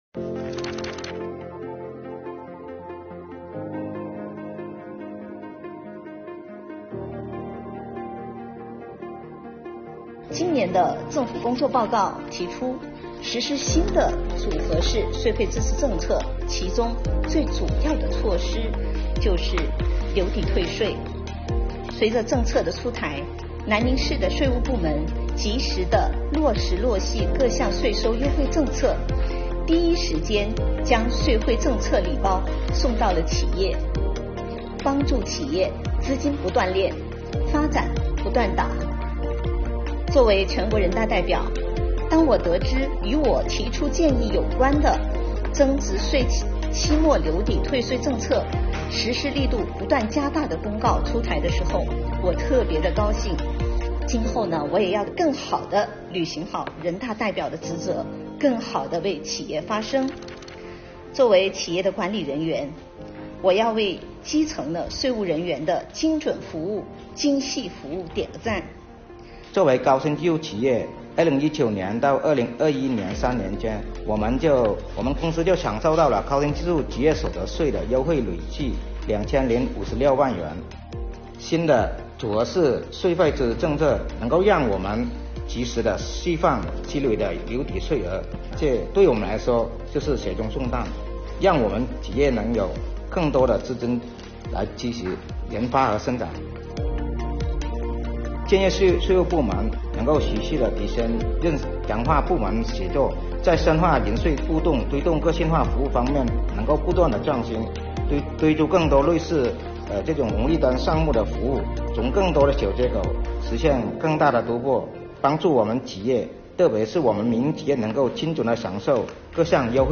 VLOG | 人大代表谈组合式税费支持政策